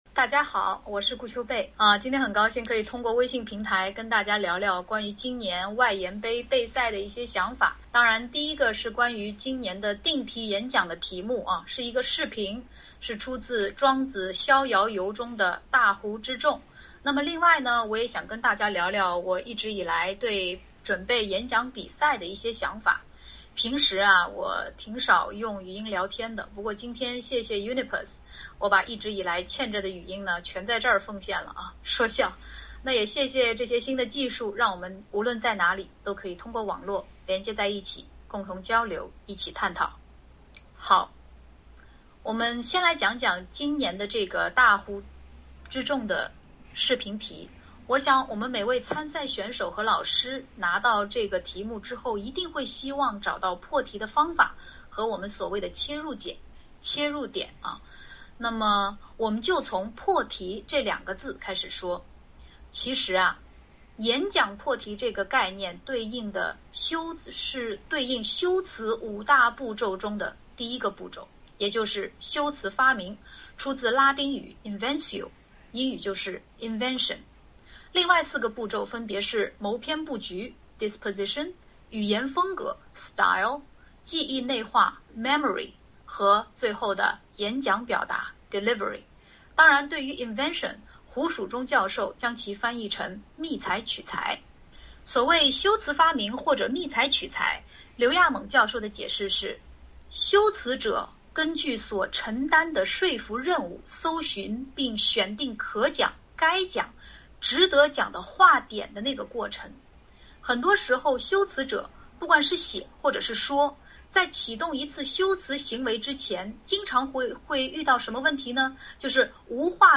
“2015定题演讲赛题解析”微信群讲座活动于10月14日晚8点—10点在轻松热烈的氛围中进行，共有来自全国各地的近千名观众报名，以微信群语音的方式收听讲座直播。